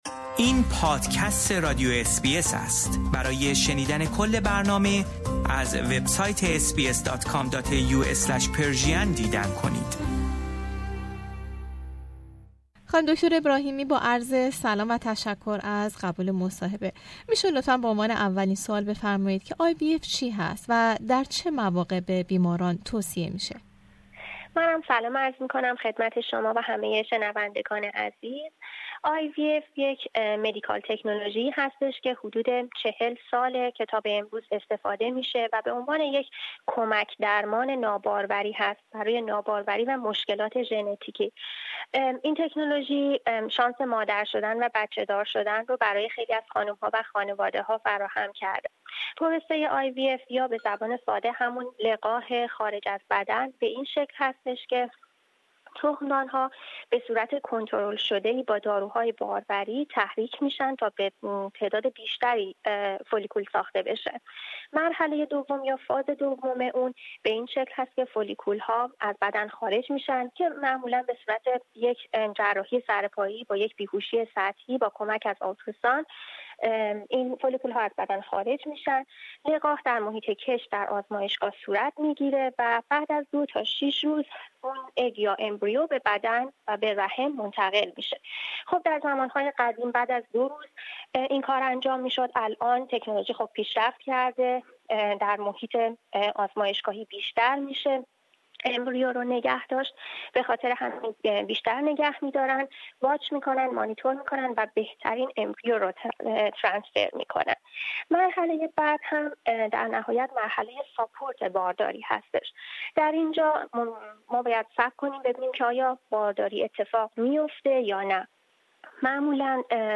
مصاحبه ای